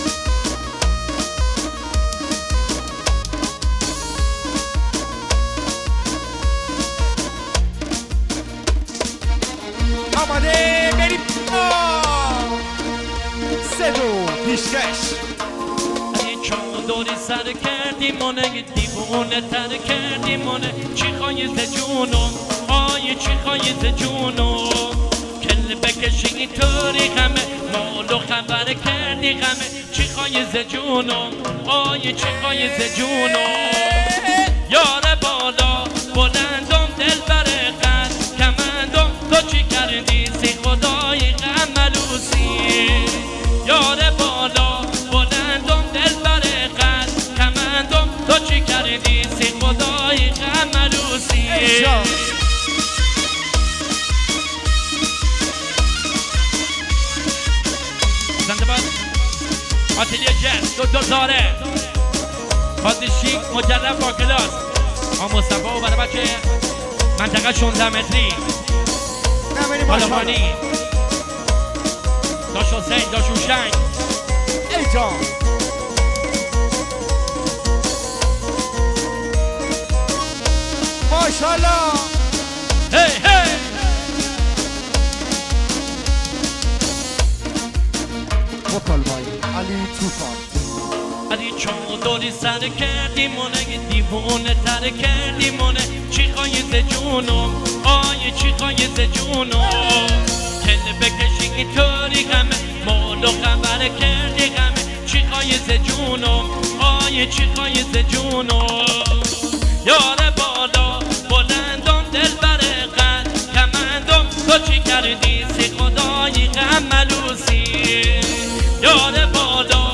ترانه محلی مجلسی لری